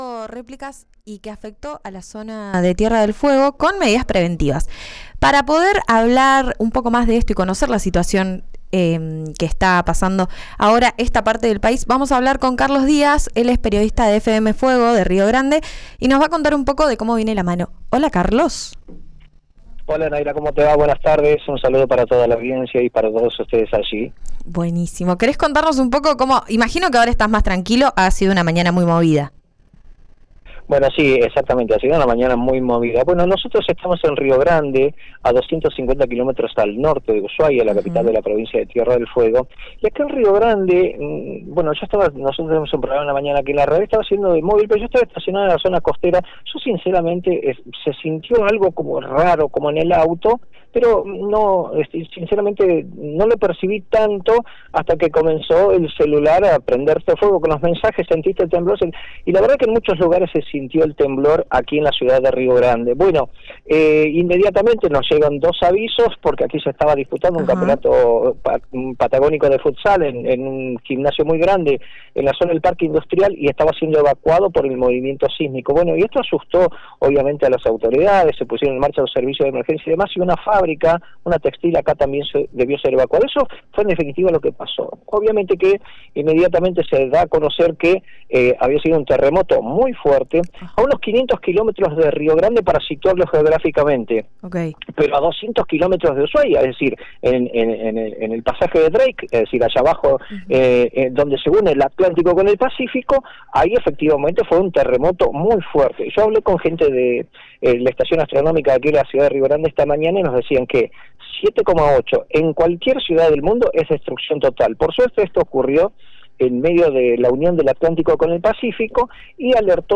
Un periodista fueguino relató en RÍO NEGRO RADIO el impacto del movimiento telúrico y la alerta del vecino país en la provincia más austral de la Patagonia argentina.